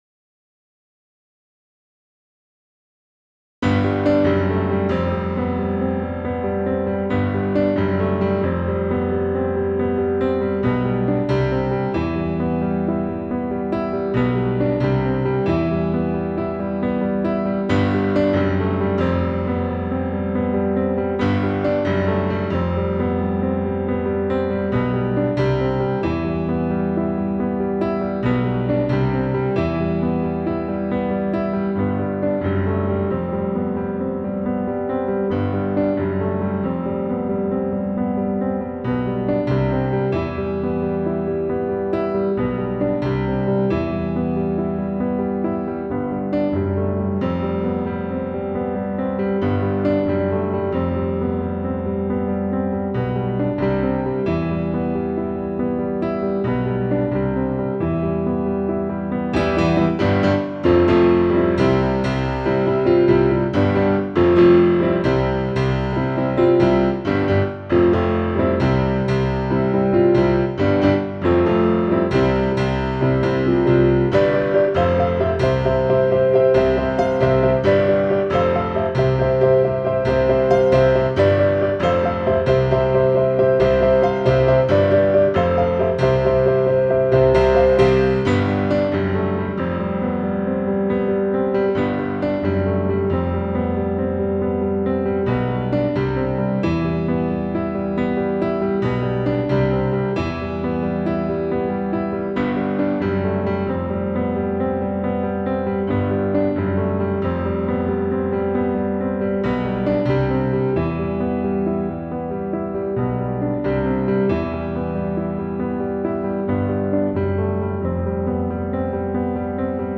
Faith Piano 2.wav